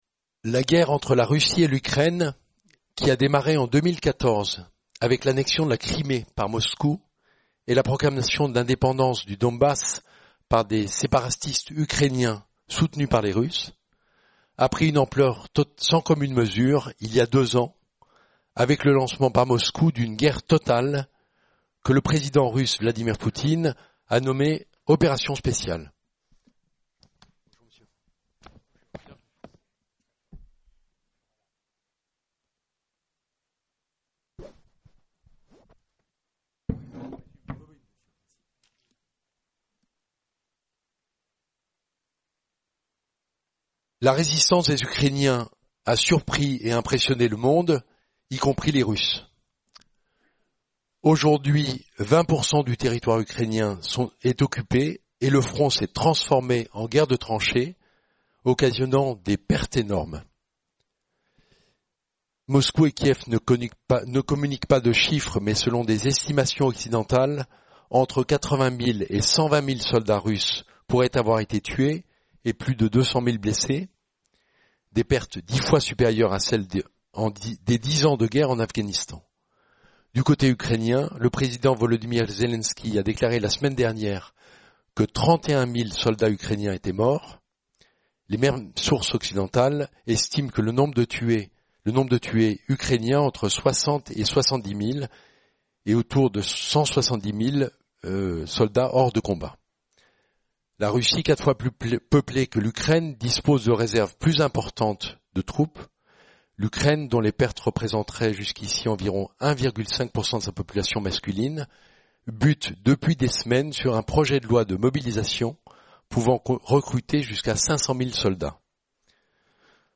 Débat